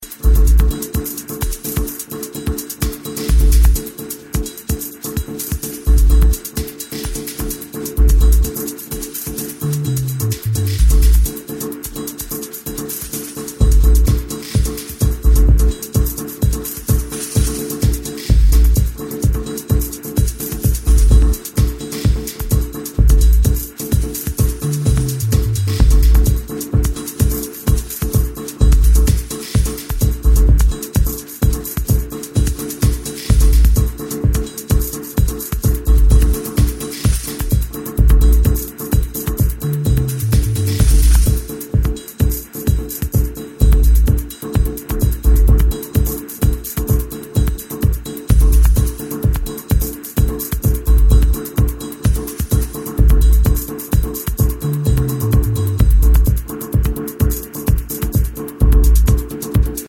一般的なパターンとは一味も二味もズラしてくるリズム、そしてザラついた質感の妙。